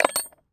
metal_small_movement_18.wav